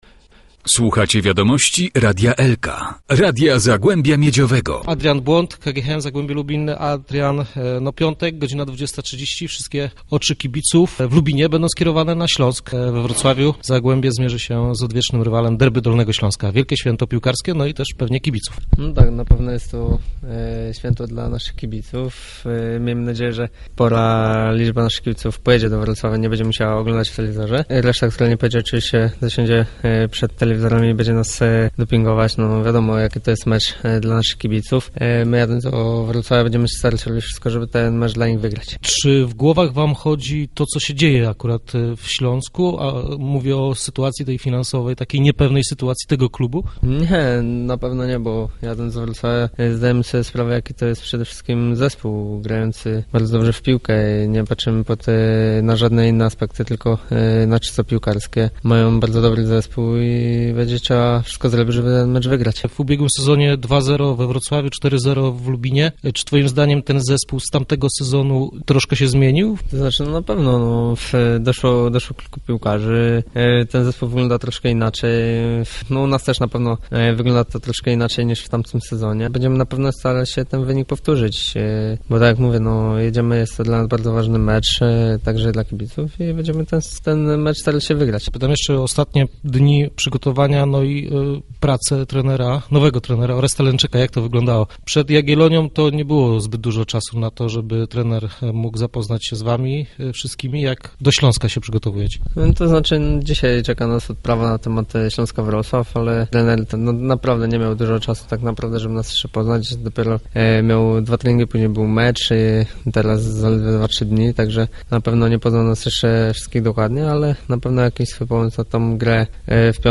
Przed wyjazdem rozmawialiśmy z pomocnikiem Zagłębia